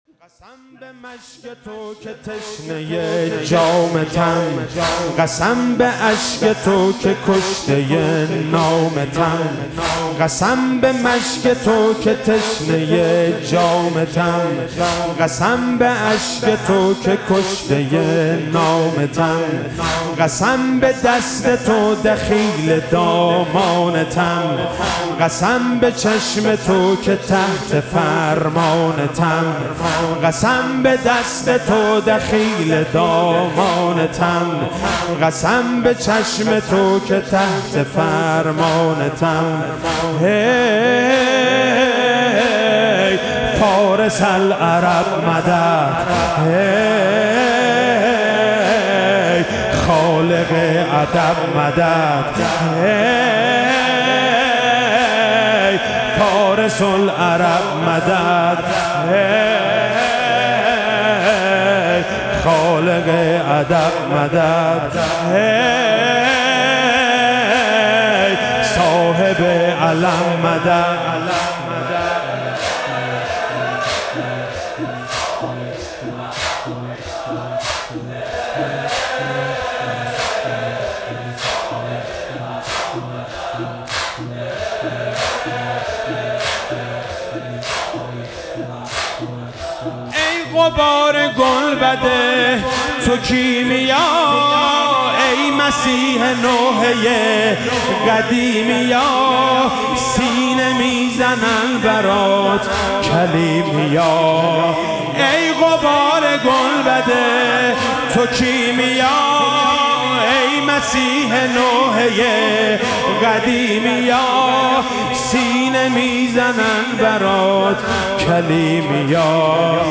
ایام فاطمیه97 - شب پنجم - زمینه - قسم به مشک تو که تشنه